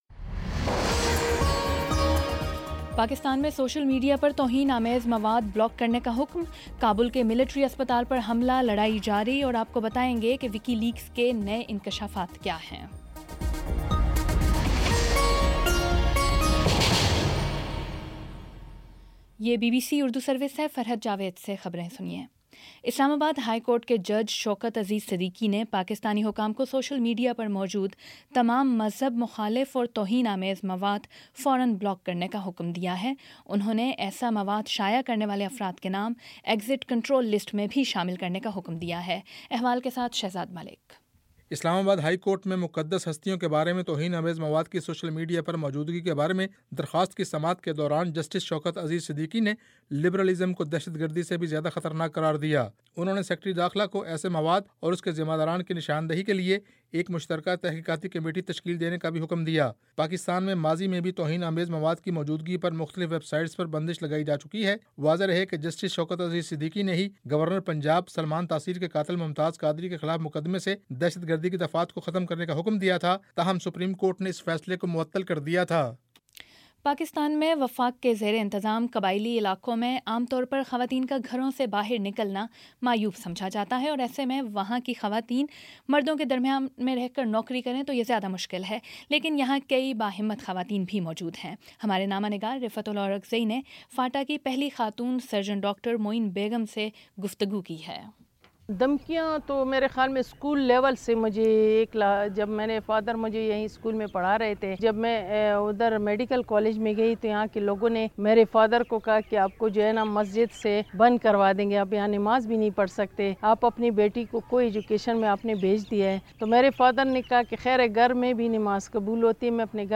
مارچ 08 : شام پانچ بجے کا نیوز بُلیٹن